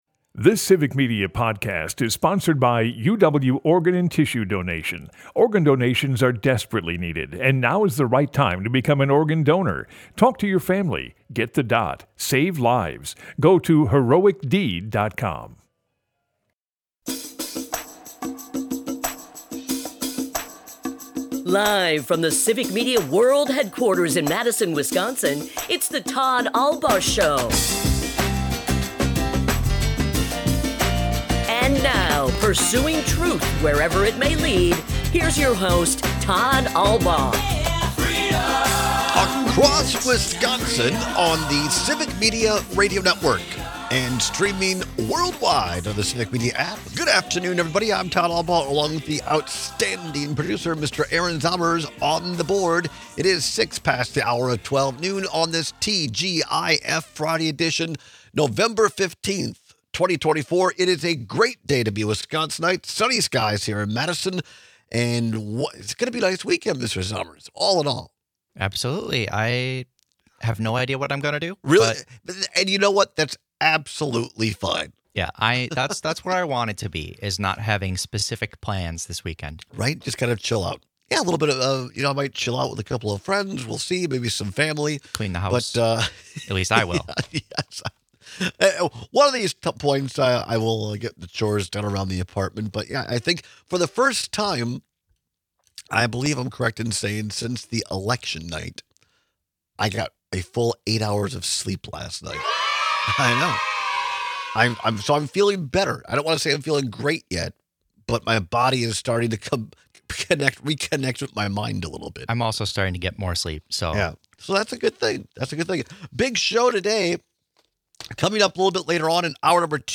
Broadcasts live 12 - 2p across Wisconsin.